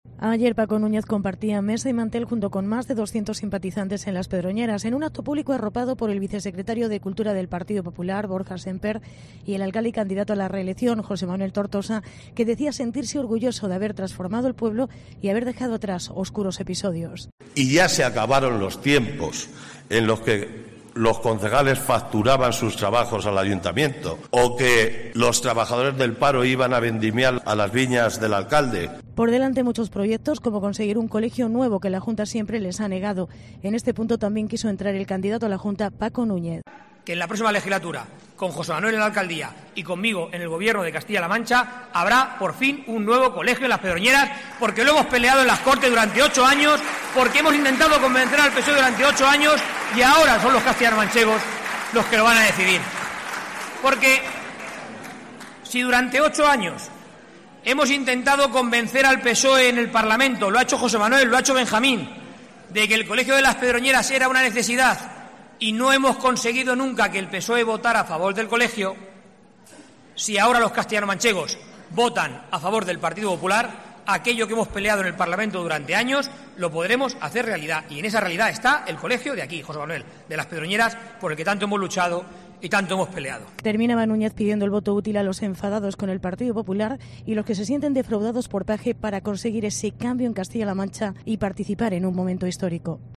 Acto del PP en las Pedroñeras